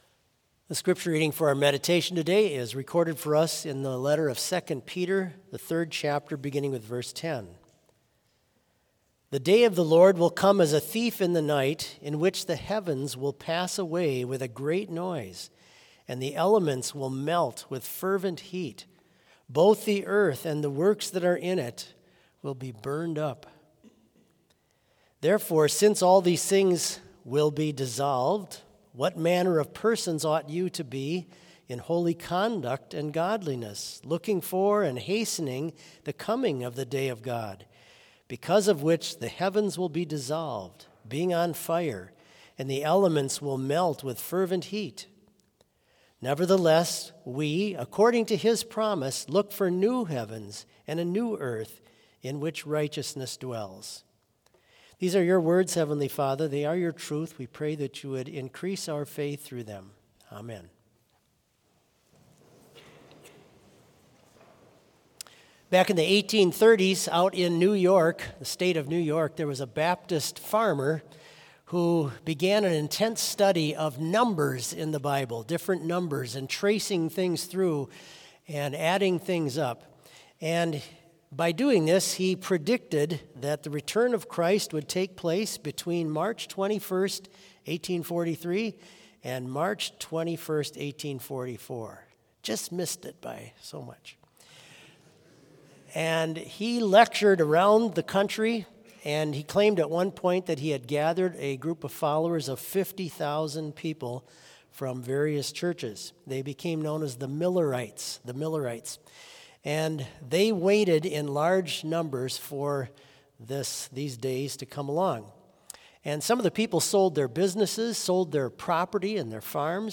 Complete service audio for Chapel - Monday, November 18, 2024